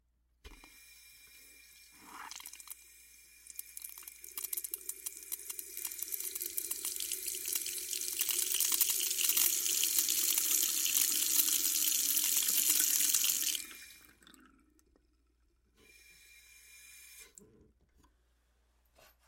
落水
描述：我从厨房水槽录下的两个样品的混合物。专为需要沉重泄漏声音的朋友而制作。无缝循环。使用Roland Edirol R09HR录制并在Adobe Audition中编辑。
标签： 下降 水槽 飞溅 跑步 漏水 下降 泄漏 液体 洞穴 水龙头
声道立体声